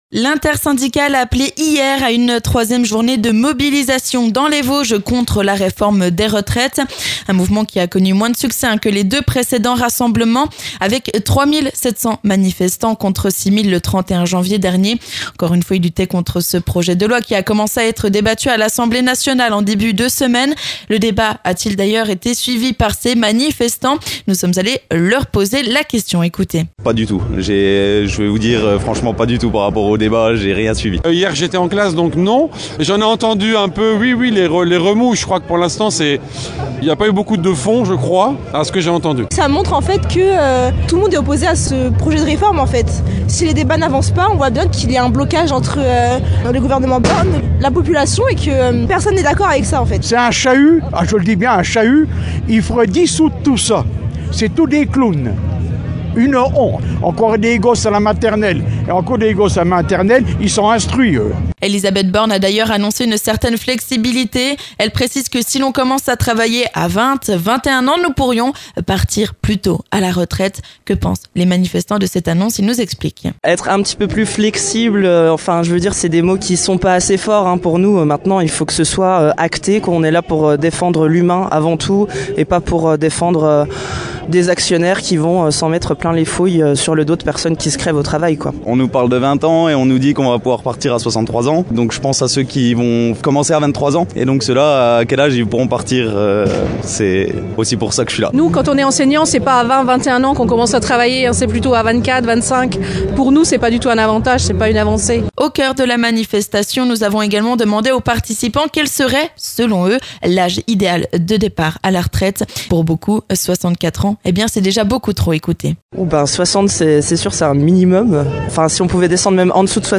Nous sommes allés tendre notre micro au milieu de la foule qui a rassemblé près de 3 700 manifestants selon les forces de l'ordre.